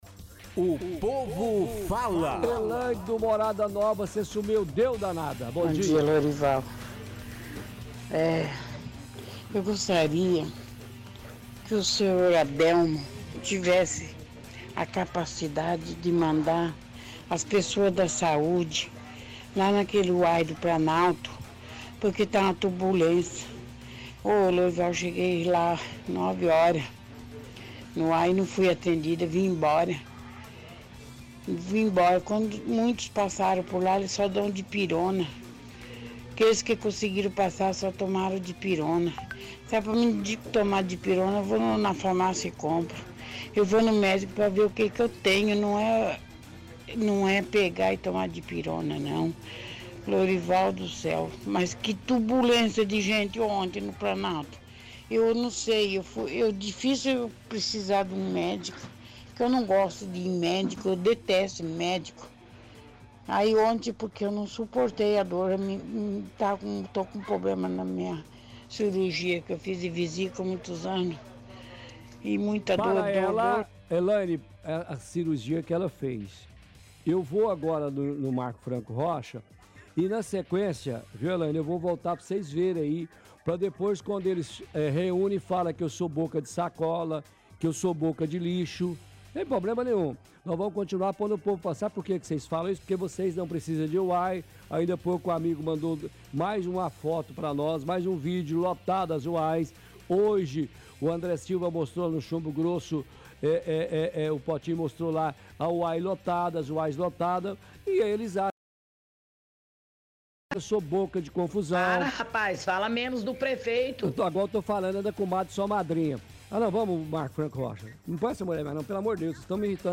– Áudio é bastante longo e a ouvinte repete várias vezes as reclamações.